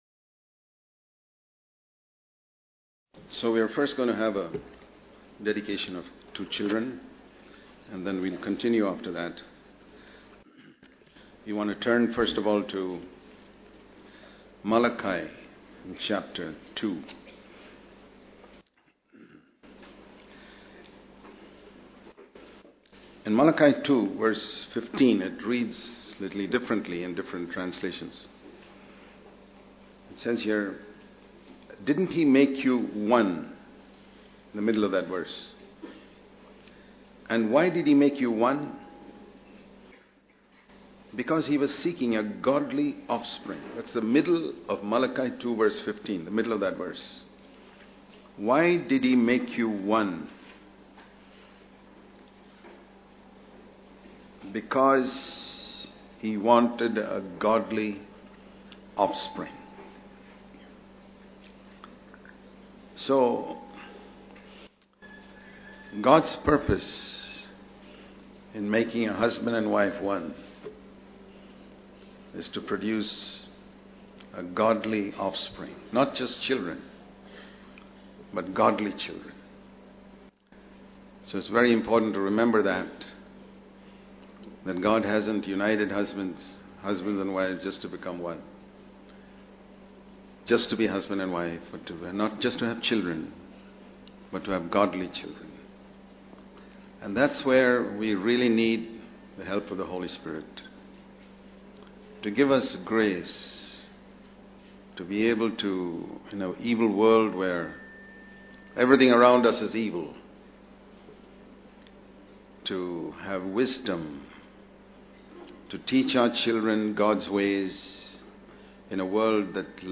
during the Sunday service at Christian Fellowship Church, Bangalore